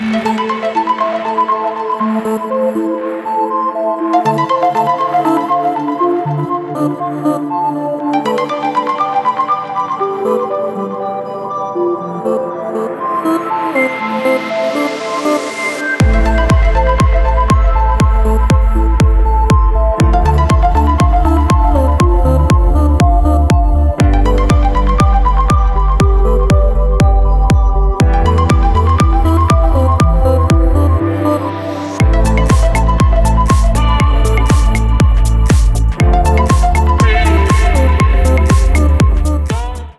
Электроника # без слов # тихие